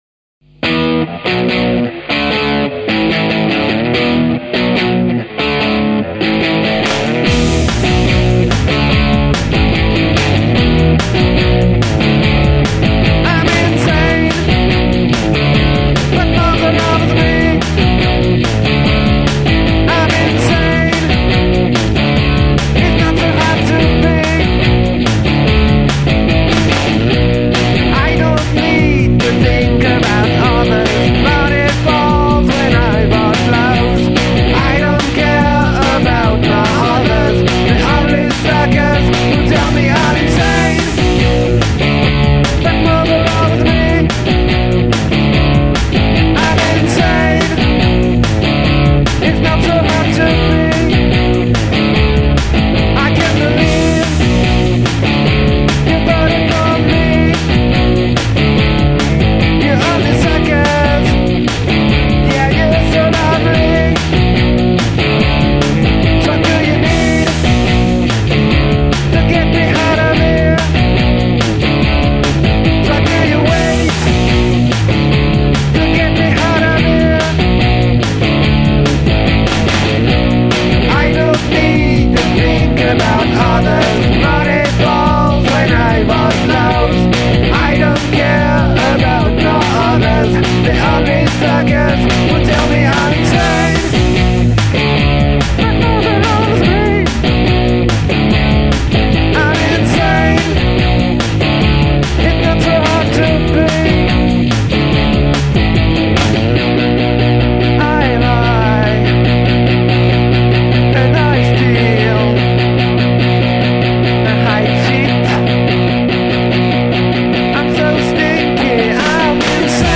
Démo